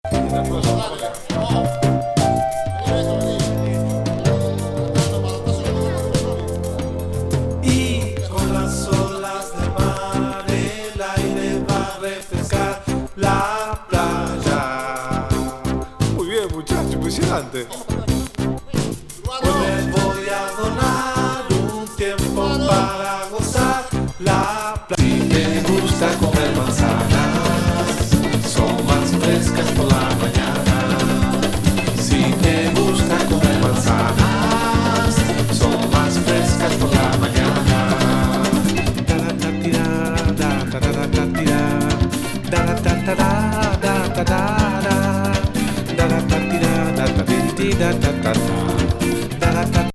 大解放極上メロウ・フュージョン
パーカッシブAOR